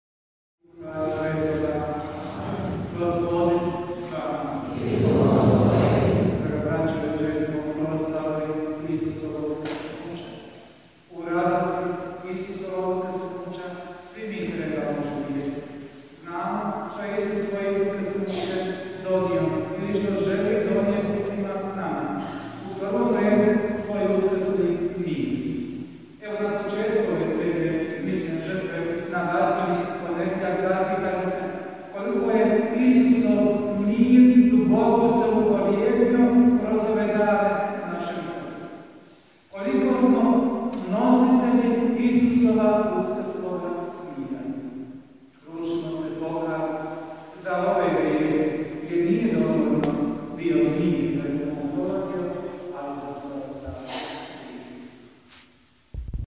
UVODNA MISAO